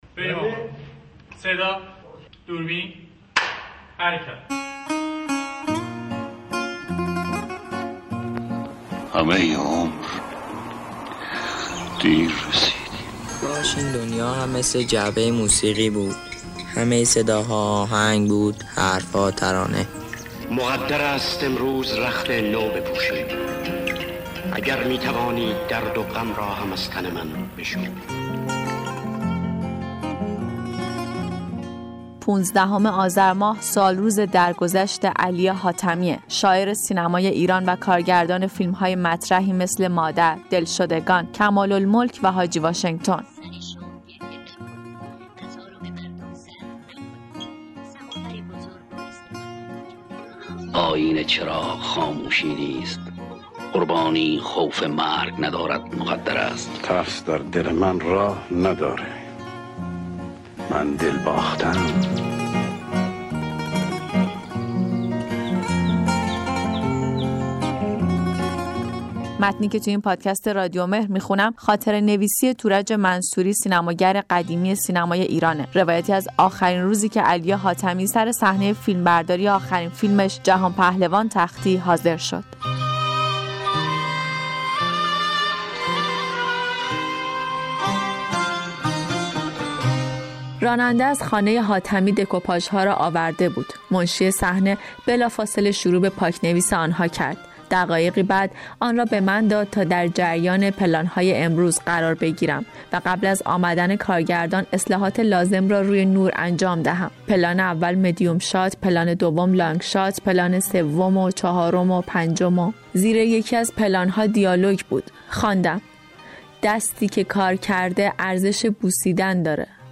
در این پادکست رادیومهر علاوه بر بازخوانی متن این دست نوشته، بخشی از موسیقی متن فیلم «مادر» و «دلشدگان» به کارگردانی علی حاتمی را می شنوید.